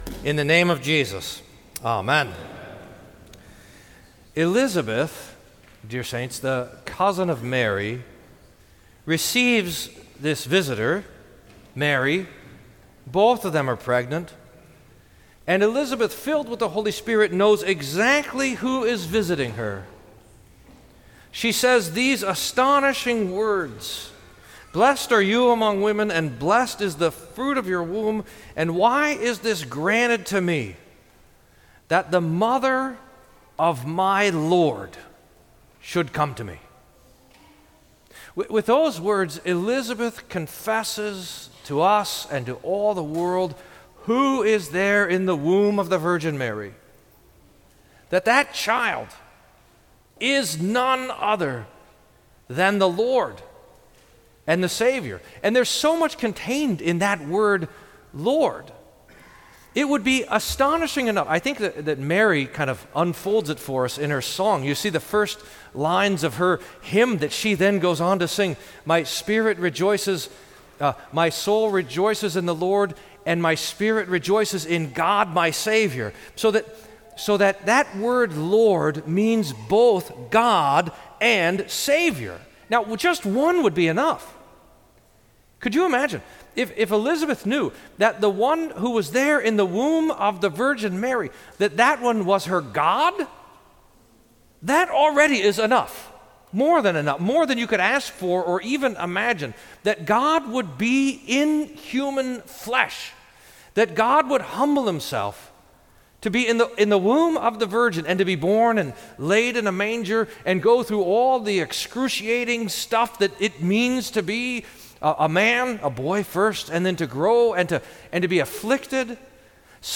Sermon for Fourth Sunday in Advent